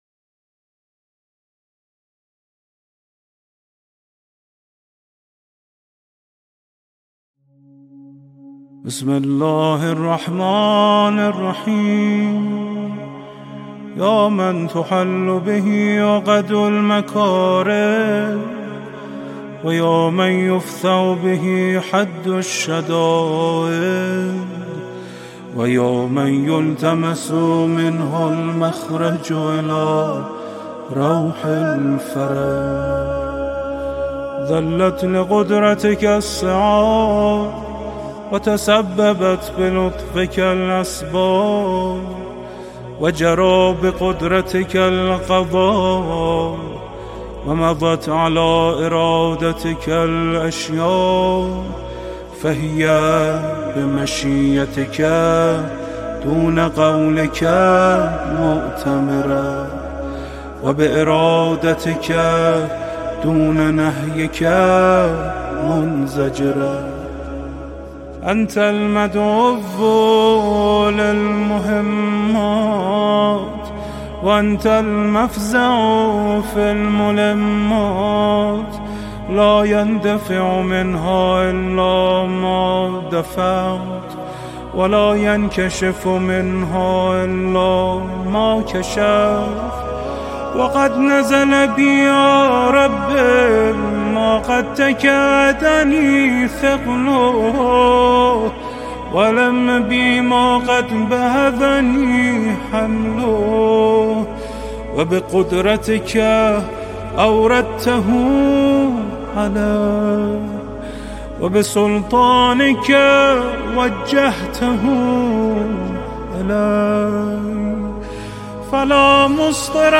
بهترین دعای صحیفه سجادیه برای حاجت(متن و صوت)